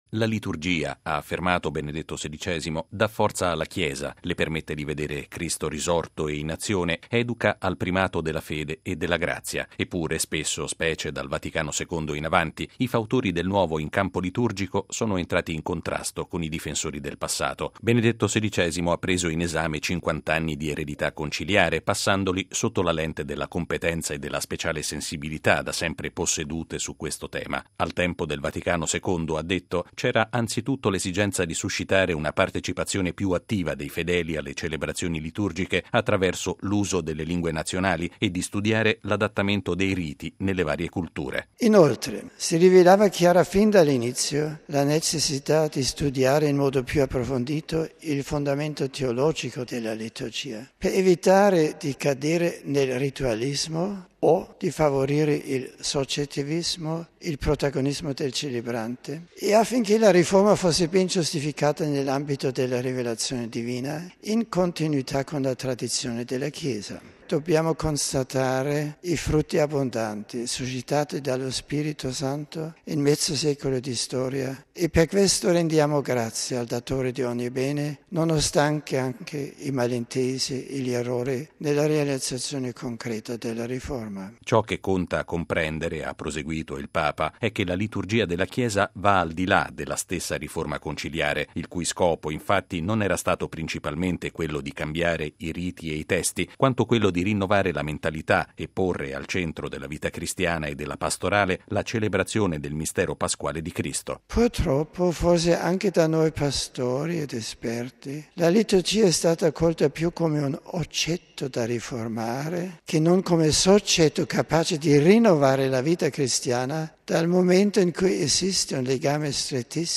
Con questa e altre considerazioni, Benedetto XVI ha celebrato il 50.mo di fondazione del Pontificio Istituto Liturgico, ricevendo in udienza in Vaticano i circa 250 partecipanti al Congresso internazionale di liturgia, promosso dal Pontificio Ateneo Sant’Anselmo. Il servizio